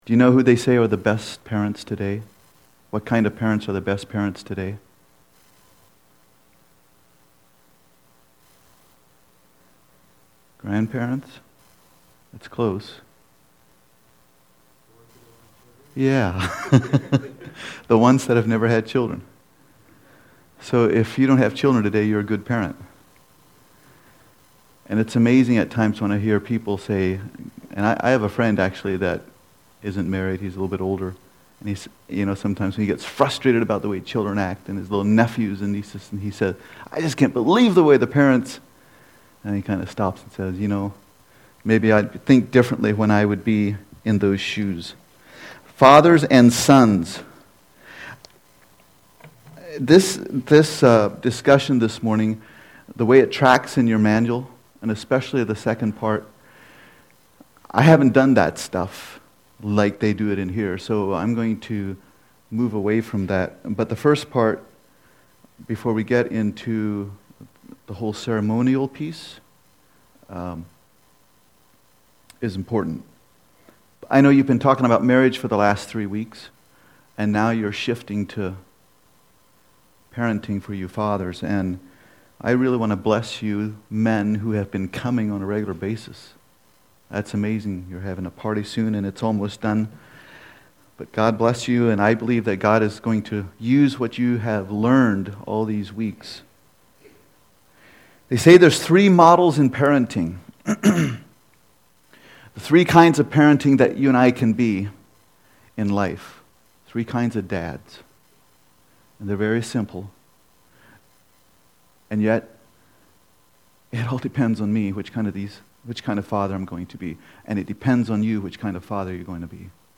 Service Type: Special Session